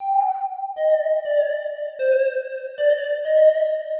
The Tech (Lead) 120BPM.wav